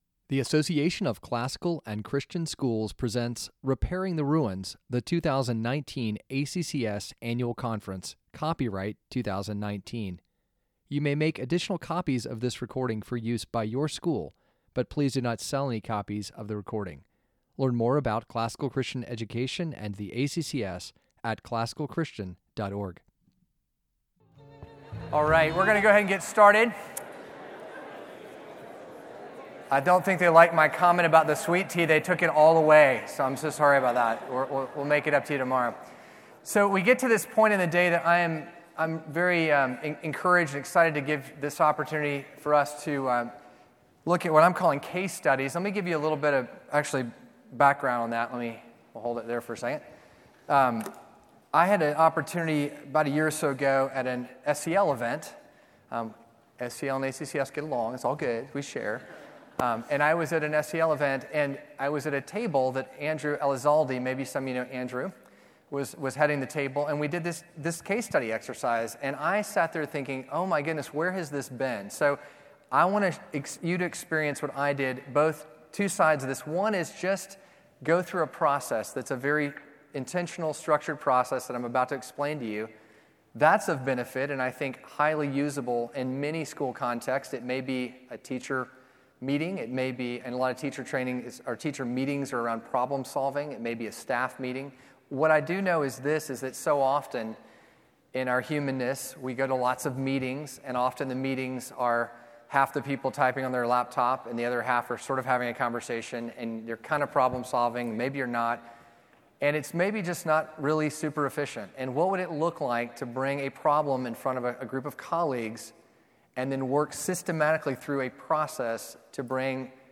Table Discussion: Case Study Discussion of Challenges Facing Your School
2019 Leaders Day Talk | 16:12 | Leadership & Strategic